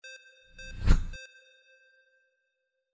Spaceship passing by 08
Stereo sound effect - Wav.16 bit/44.1 KHz and Mp3 128 Kbps
previewSCIFI_SPACESHIP_PASSBY_WBHD08.mp3